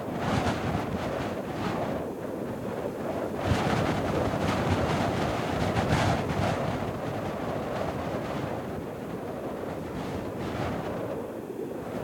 WindHeavy1.ogg